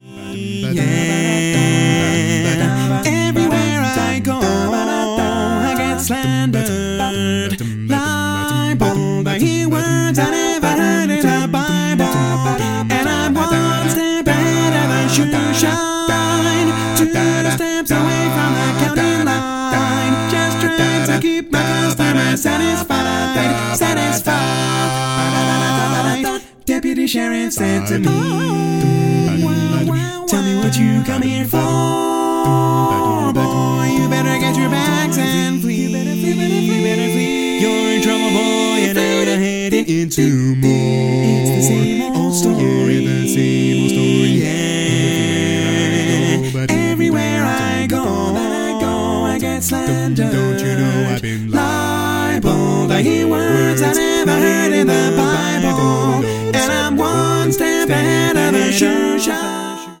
Full mix only